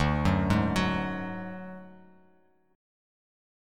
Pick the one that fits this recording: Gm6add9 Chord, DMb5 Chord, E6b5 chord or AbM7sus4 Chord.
DMb5 Chord